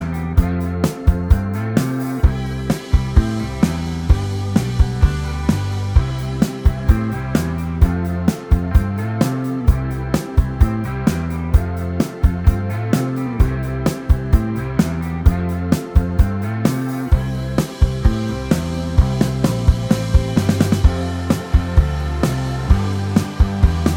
Minus Lead Guitars Pop (1980s) 2:53 Buy £1.50